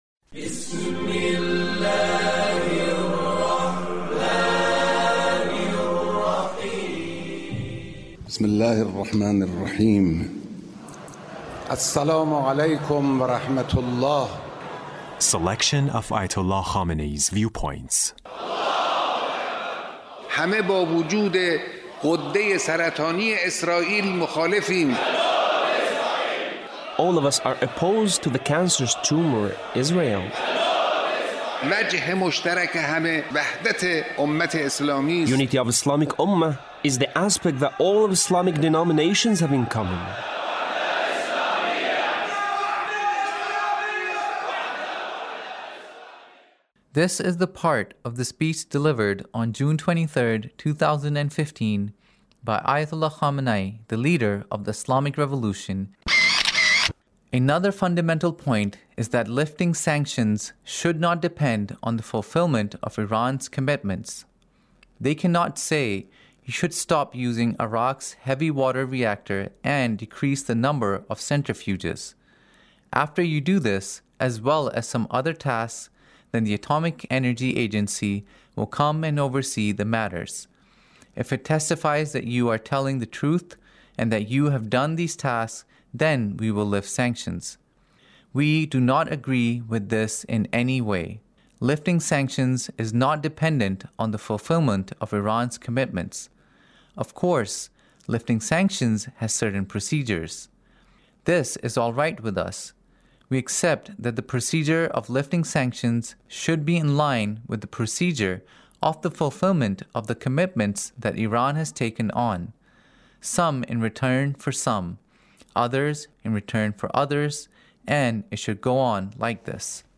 Leader's Speech in a Meeting with the Government Officials